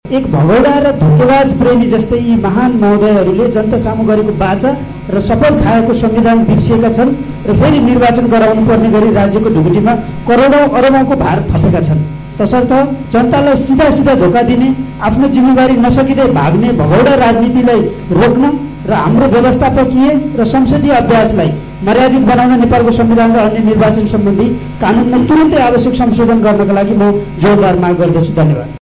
राष्ट्रिय सभाका सांसद पदम परियारले ठूलो संख्यामा जनप्रतिनिधिहरूले कार्यकाल पूरा नहुँदै राजीनामा दिएकोमा आपत्ति जनाए। उनले जनादेश बिर्सिएर भगौडा र धोकेबाज प्रेमीजस्तै जनप्रतिनिधि भागेको टिप्पणी गरे।